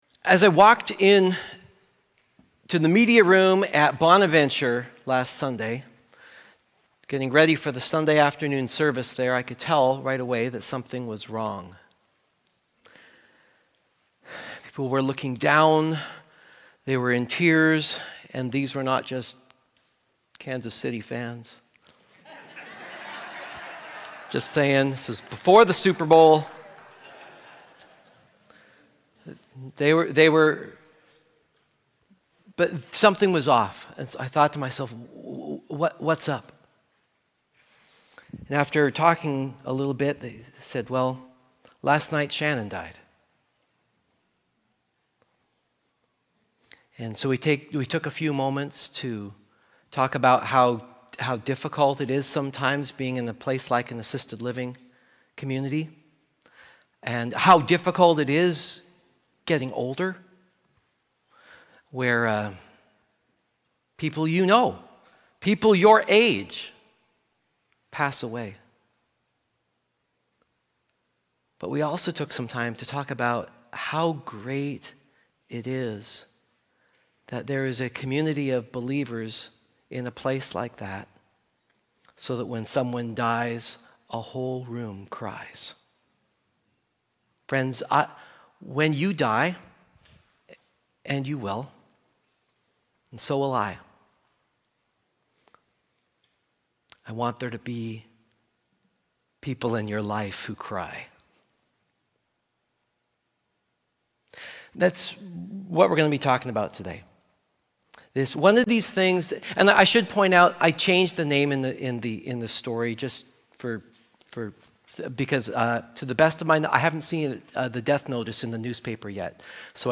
Sermons and other audio content from Columbia Grove Covenant Church of East Wenatchee Washington.